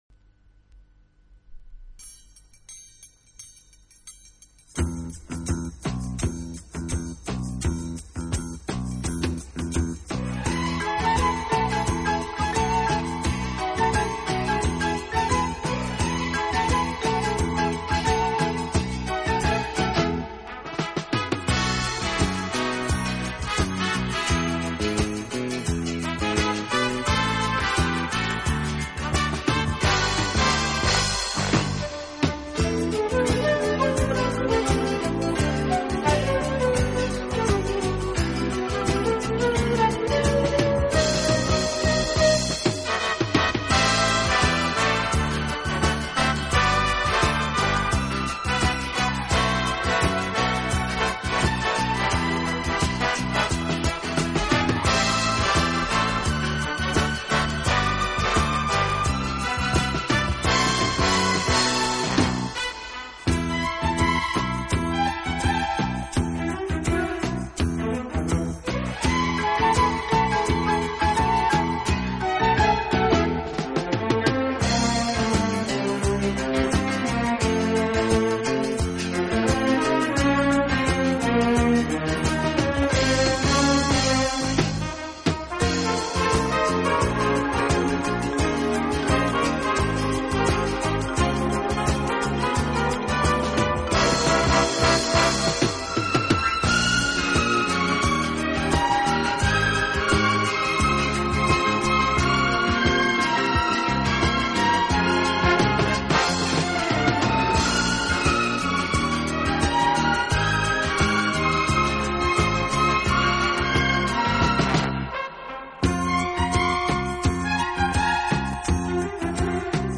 brings his lush orchestral sound up to date with